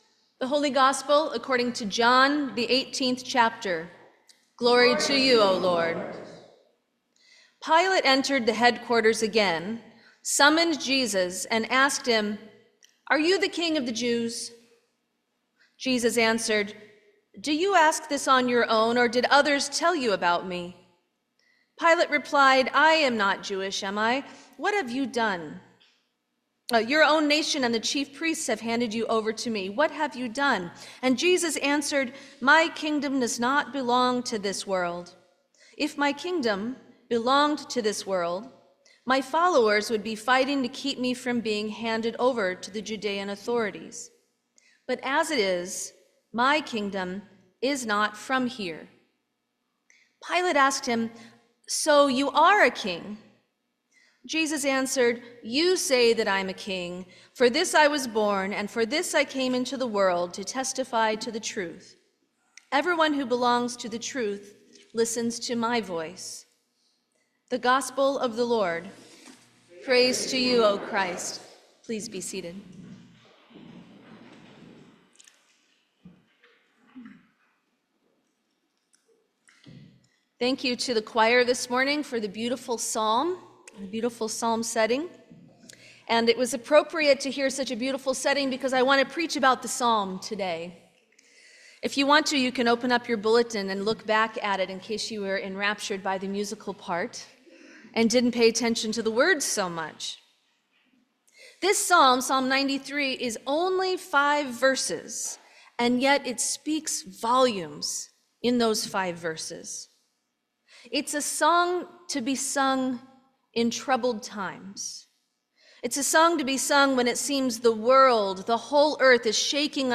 Sermon for Christ the King Sunday 2024